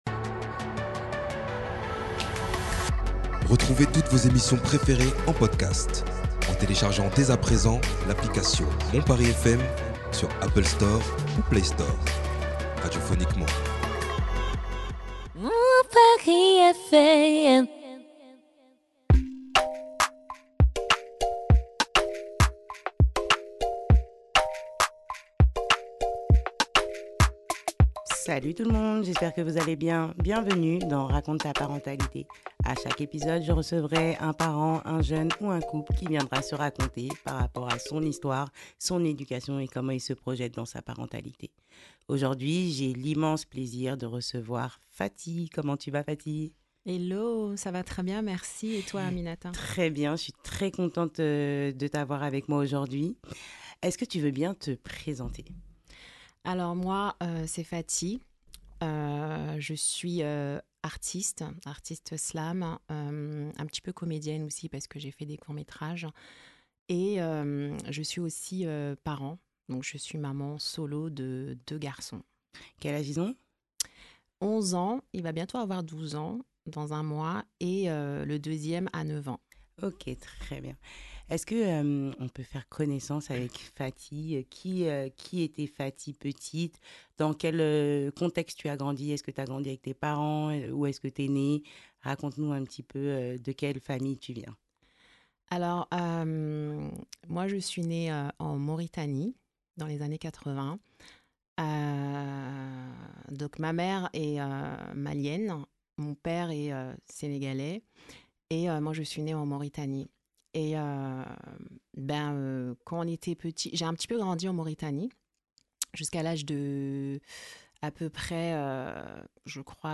Dans Raconte ta parentalité, chaque épisode donne la parole à un parent ou un couple qui partage son vécu, ses joies, ses défis et son parcours de vie.
Écoutez ce podcast jusqu’au bout… vous aurez peut-être la chance d’entendre son slam poignant, une véritable ode à la résilience et à la lutte pour les droits des femmes.